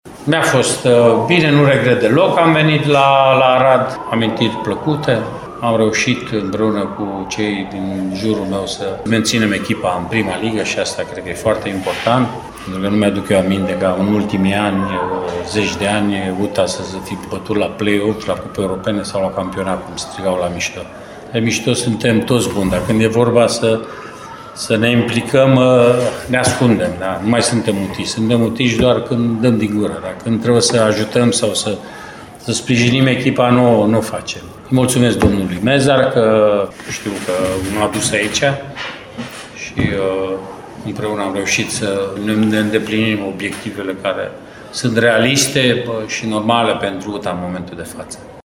La conferința post-meci, Rednic a adăugat că rămâne cu amintiri plăcute de la Arad: